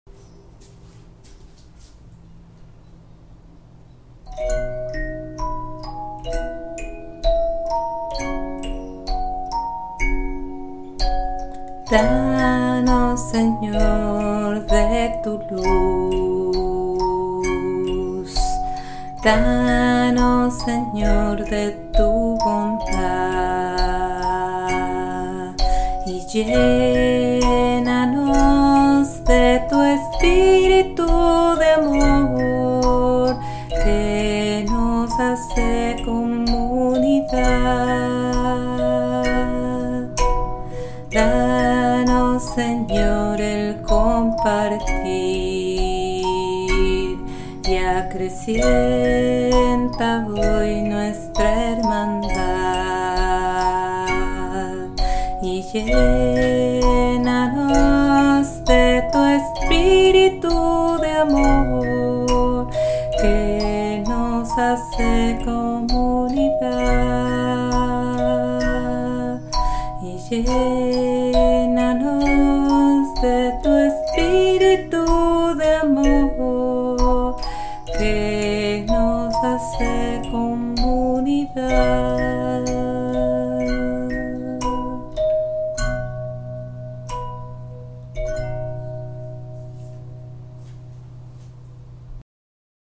CANCION